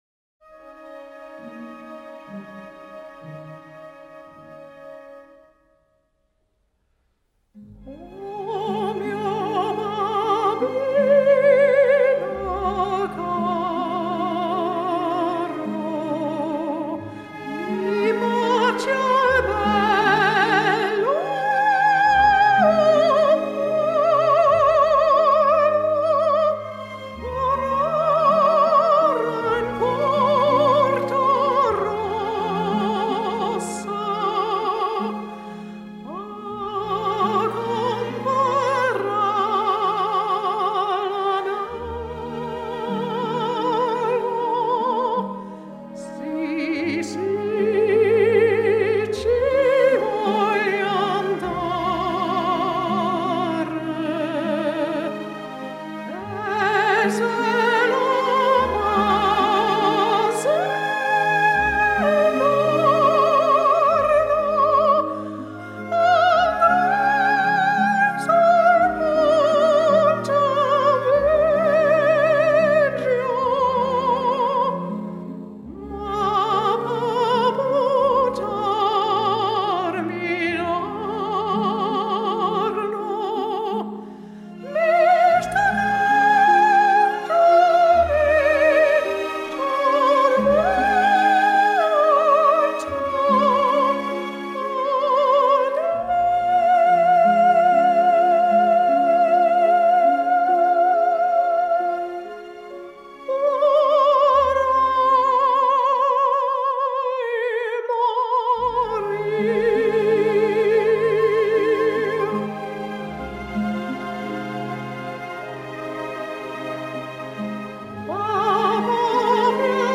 Sängerin
(Arie aus der Oper Gianni Schicchi von Giacomo Puccini)